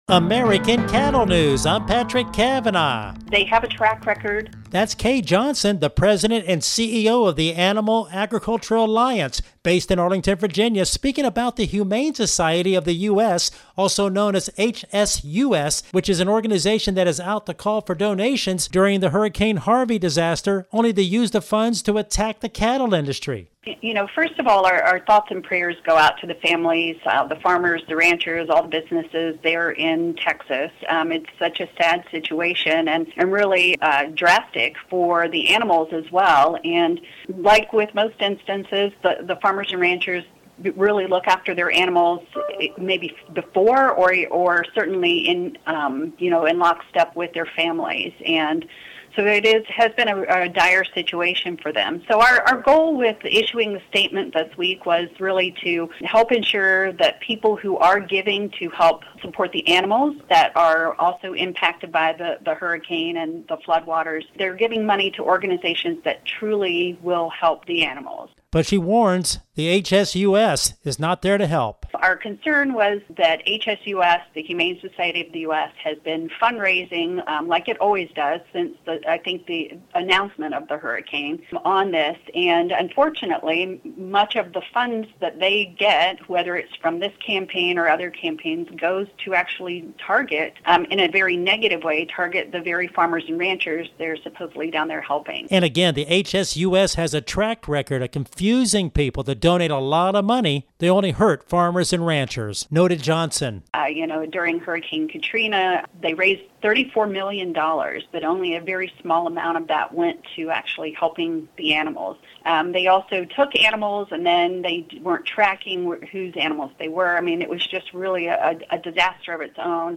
Listen to her comments here: